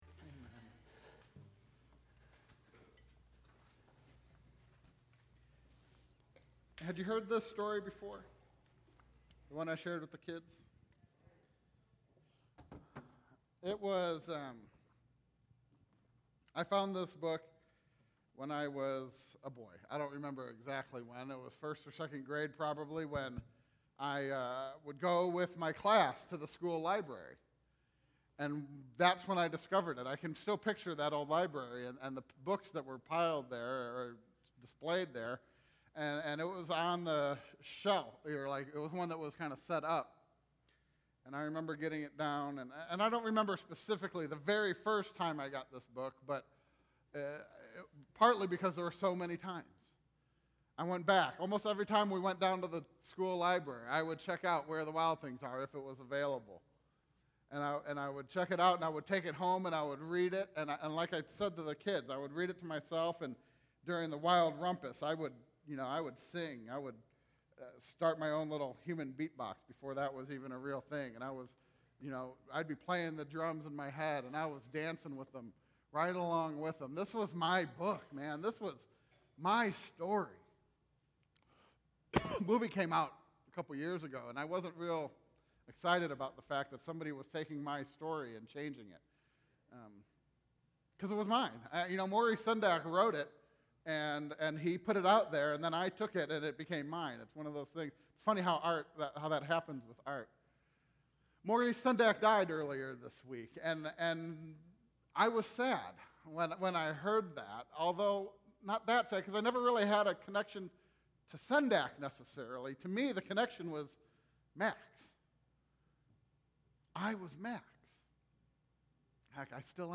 Click here to listen to the sermon: And It Was Still Hot